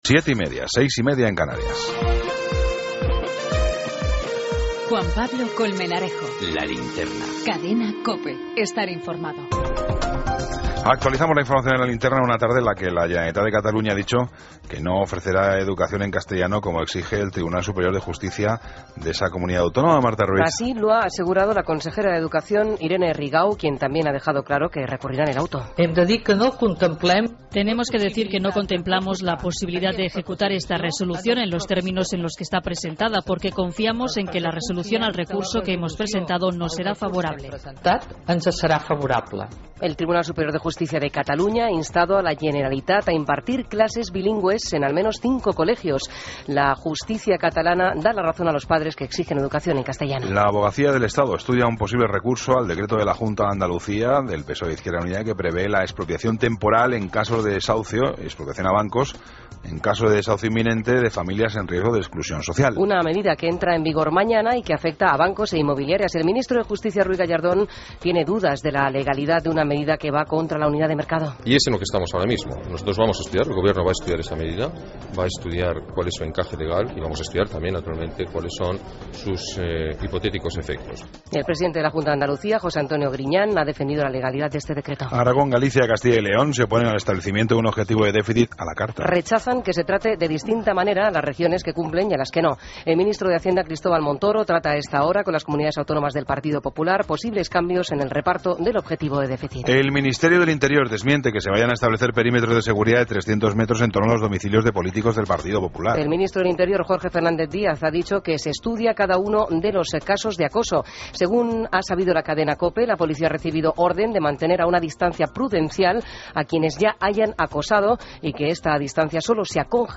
Ronda de corresponsales. Entrevista a Javi Nieves, libro 'Generación EGB'.